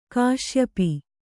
♪ kāśyapi